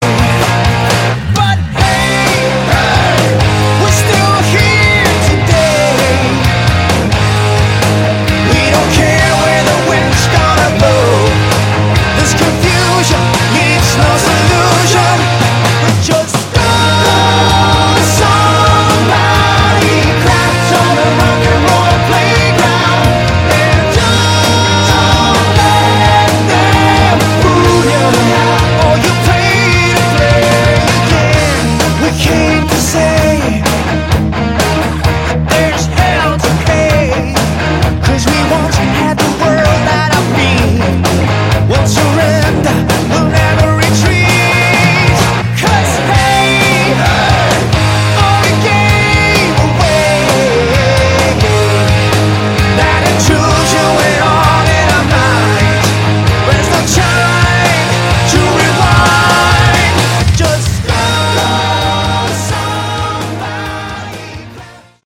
Category: Melodic Rock
guitar
lead vocals
bass, backing vocals
drums